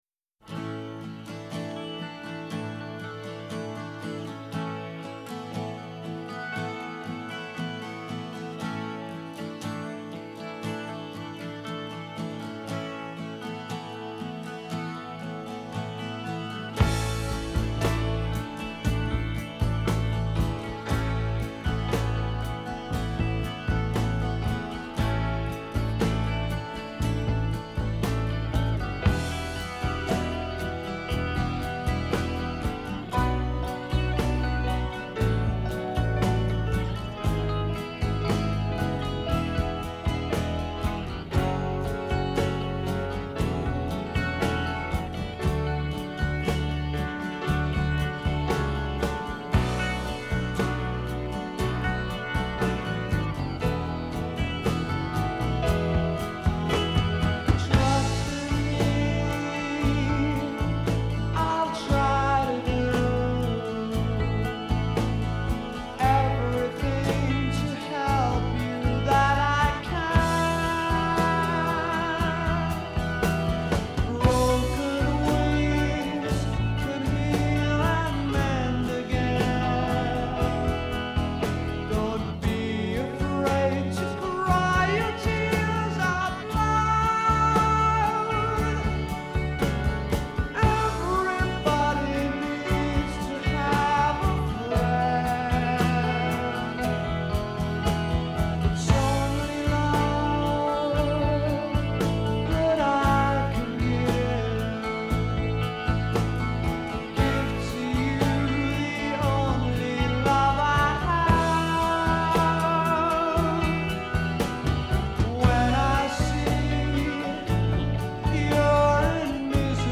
Country: Rock, Hard Rock, Blues Rock